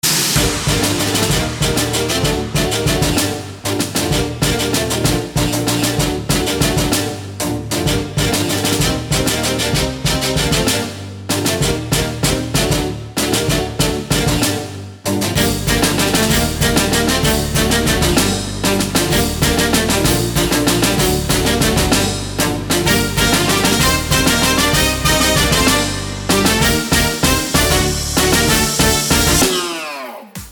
• Качество: 320, Stereo
громкие
красивые
электронная музыка
без слов
house